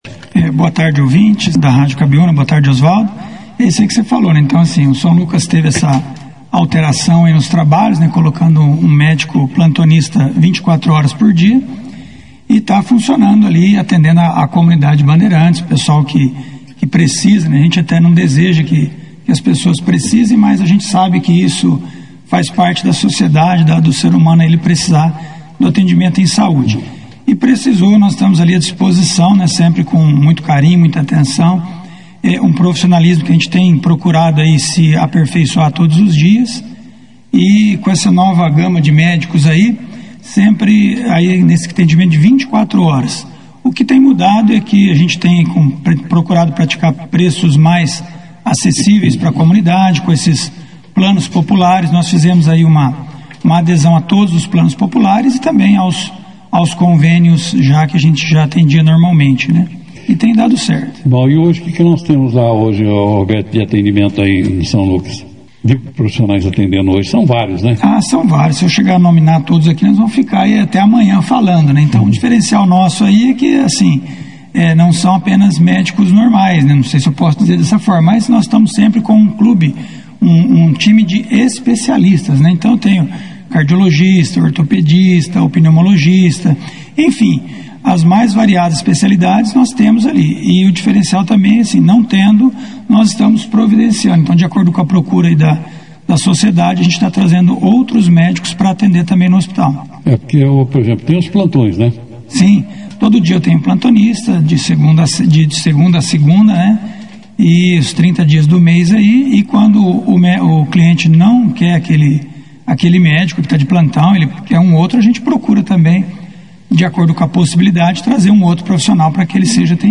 Durante a entrevista, ele falou sobre os atendimentos prestados pelo hospital, detalhando as diversas especialidades médicas oferecidas pela instituição.